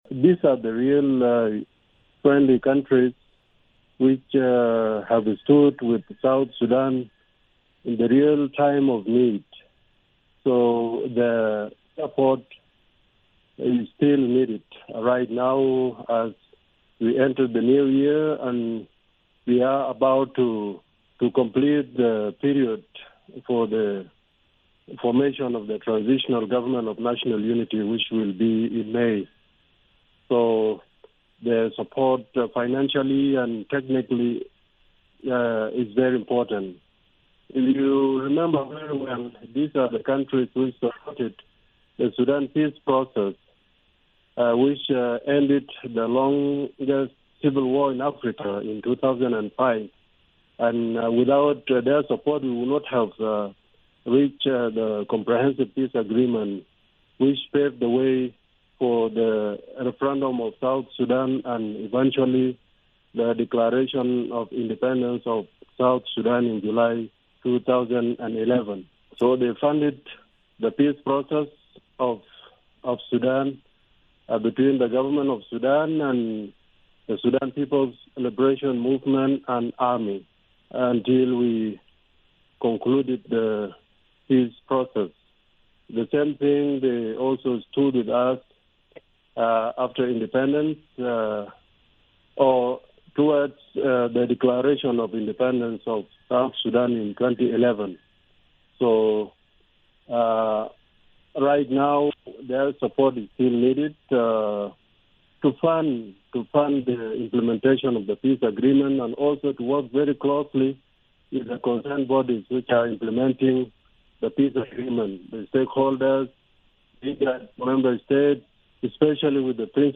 Speaking on Phone from Nairobi